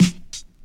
• Old School Hip-Hop Snare F Key 191.wav
Royality free snare sample tuned to the F note. Loudest frequency: 1030Hz
old-school-hip-hop-snare-f-key-191-FcU.wav